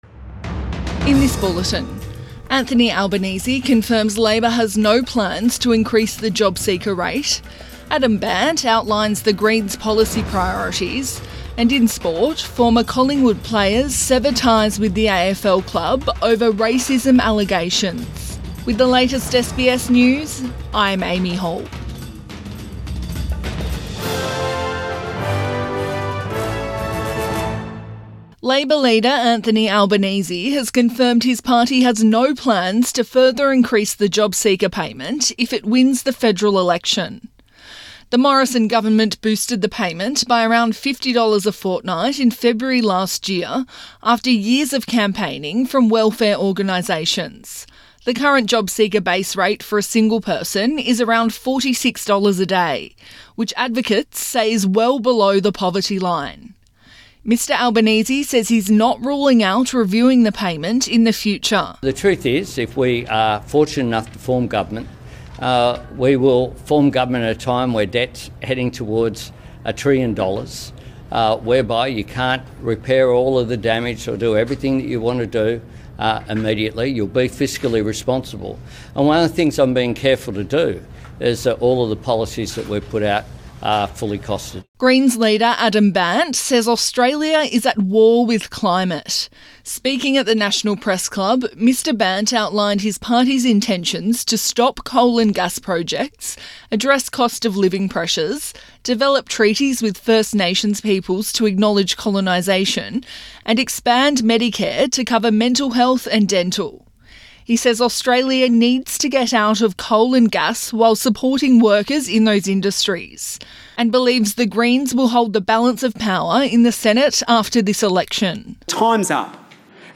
PM bulletin 13 April 2022